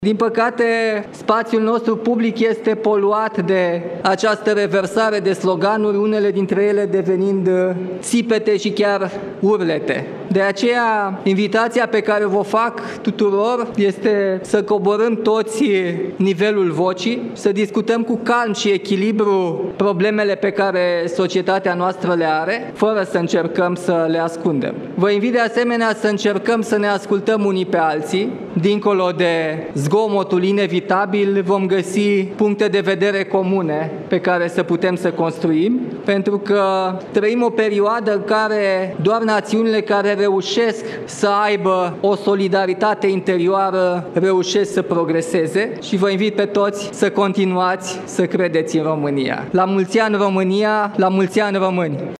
O radiografie a României – așa a arătat discursul președintelui Nicușor Dan din deschiderea recepției de Ziua Națională de la Palatul Cotroceni.